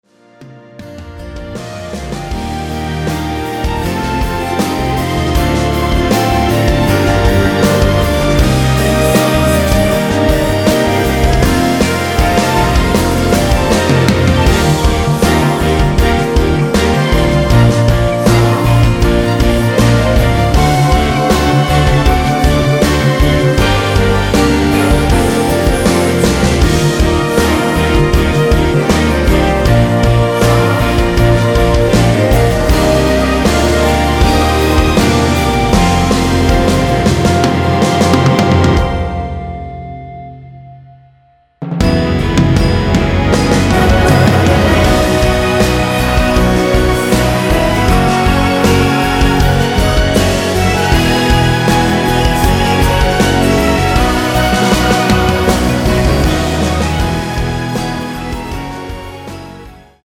원키에서(-1)내린 멜로디와 코러스 포함된 MR입니다.
Bb
앞부분30초, 뒷부분30초씩 편집해서 올려 드리고 있습니다.